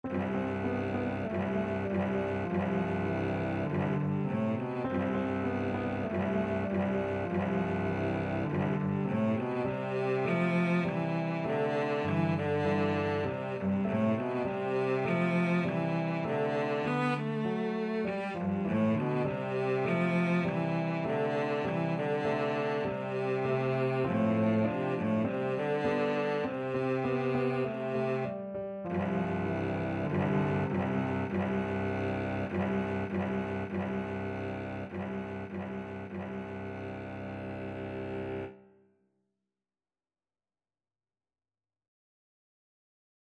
A beginners piece with a rock-like descending bass line.
March-like
4/4 (View more 4/4 Music)
Arrangement for Cello and Piano